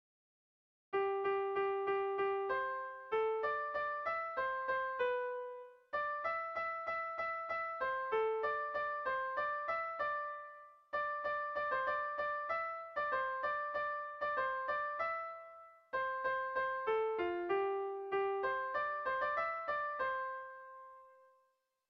Zortziko txikia (hg) / Lau puntuko txikia (ip)
ABDE